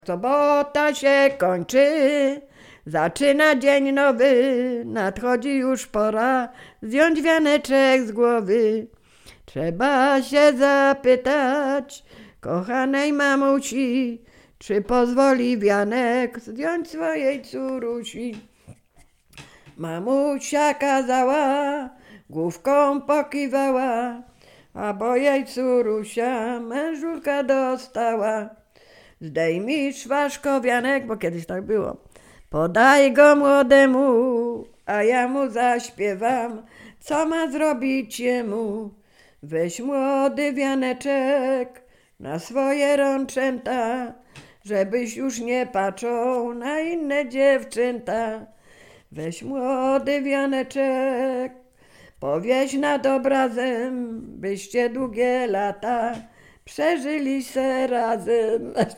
Sieradzkie
Przyśpiewki
oczepinowe weselne przyśpiewki